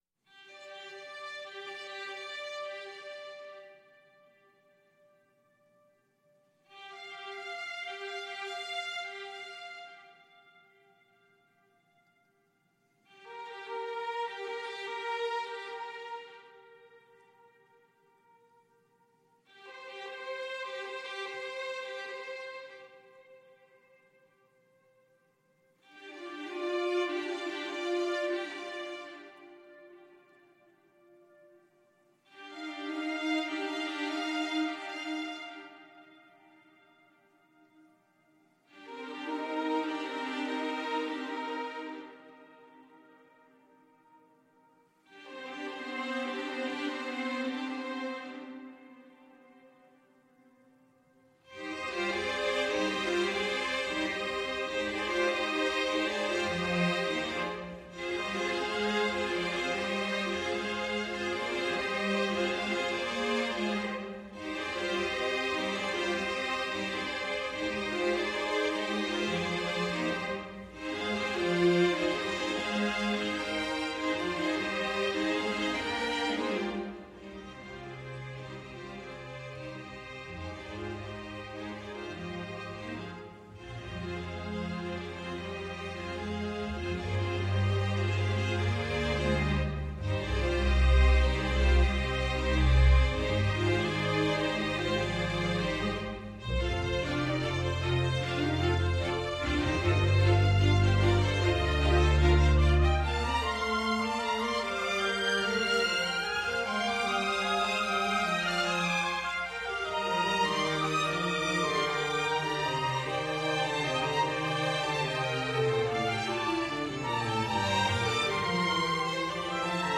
Moins marqué, l’aspect western reste présent.